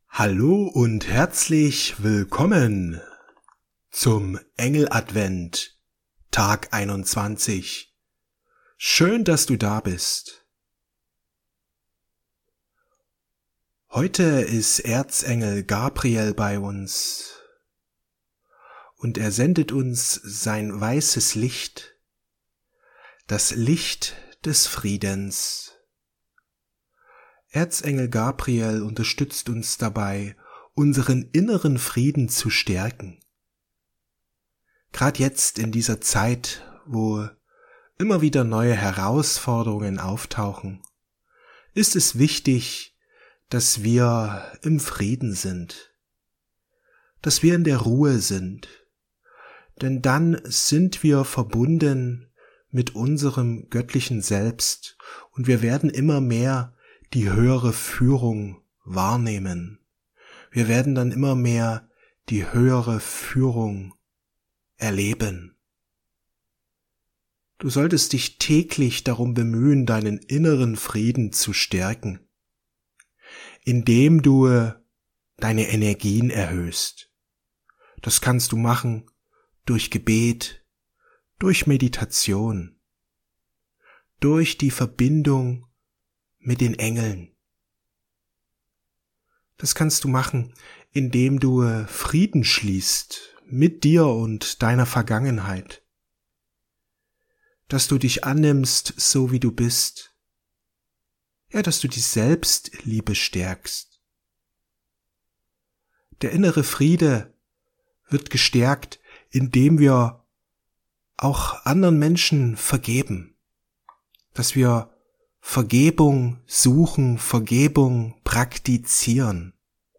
Im Frieden sein Meditation mit Erzengel Gabriel